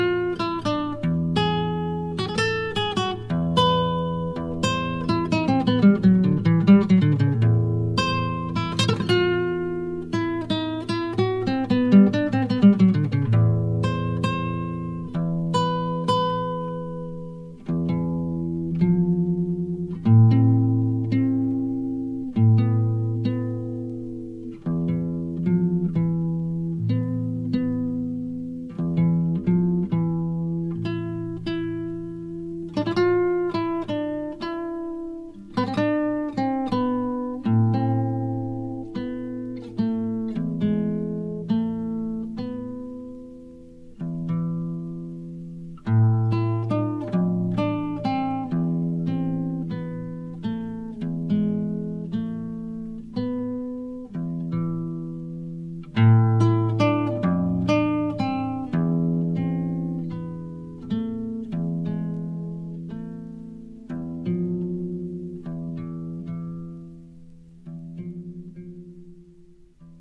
Recorded in 1980